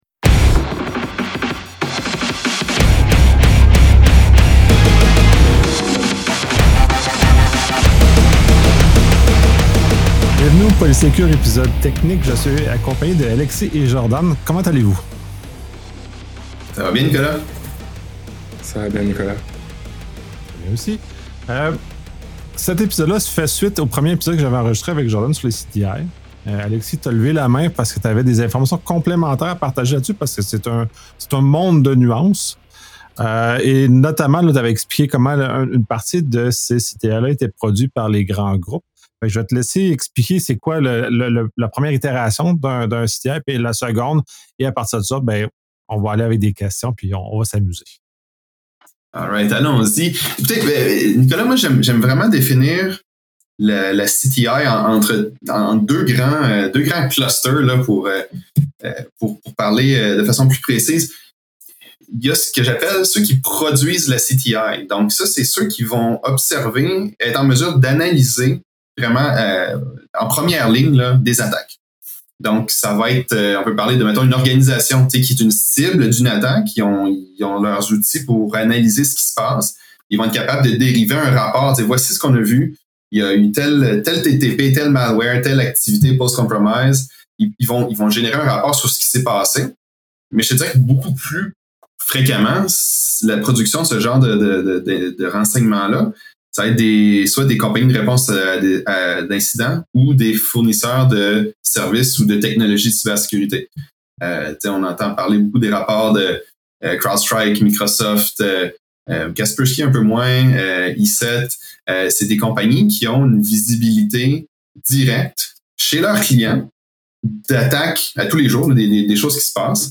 La conversation explore les défis techniques, organisationnels et éthiques auxquels font face les professionnels de la sécurité dans ce domaine en constante évolution.